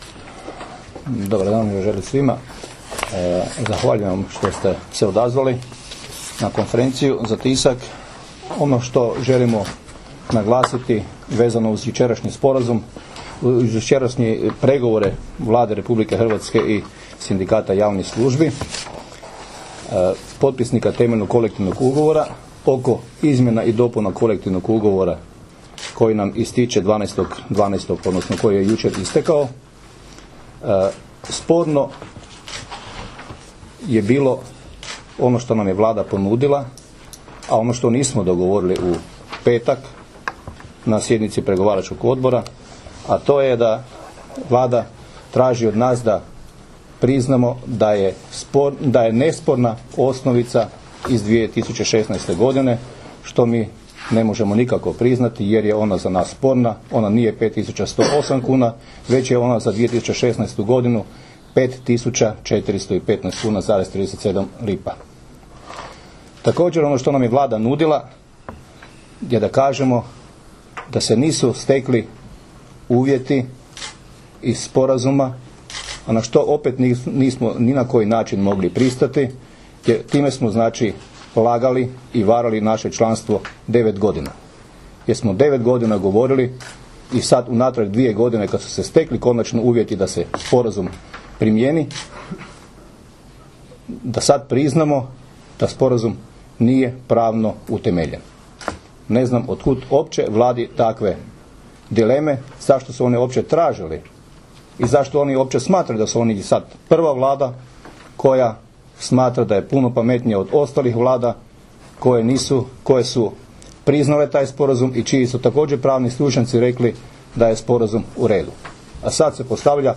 Čelnici sindikata javnih službi na konferenciji za medije 12. prosinca 2016. obrazložili su razloge zbog kojih nije došlo do dogovora s Vladom RH oko realizacije Sporazuma o osnovici za plaće u javnim službama.